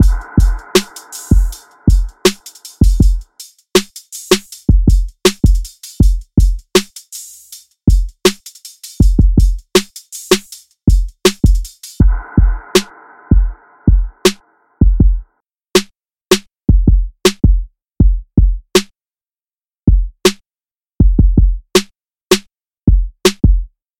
Tag: 80 bpm Hip Hop Loops Drum Loops 4.04 MB wav Key : Unknown